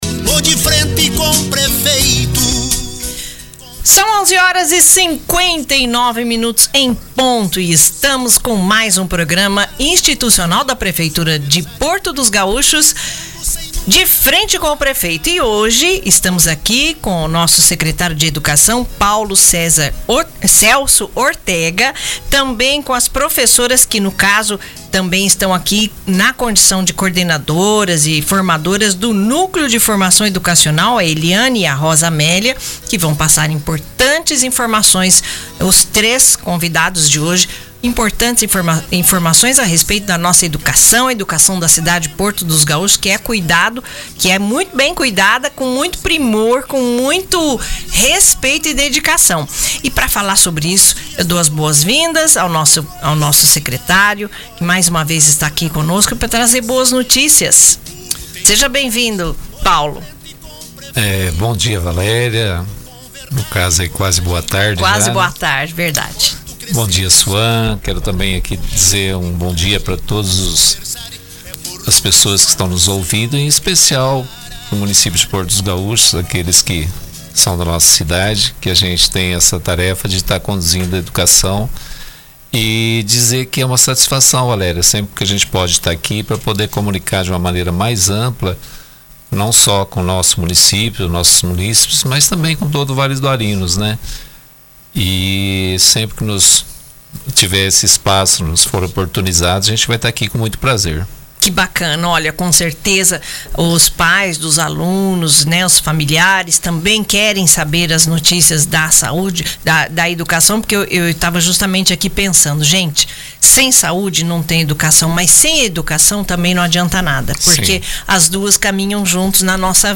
No dia 12 de setembro, sexta-feira, a Prefeitura de Porto dos Gaúchos realizou mais uma edição do programa De Frente com o Prefeito na Rádio Tucunaré, 89,3 FM.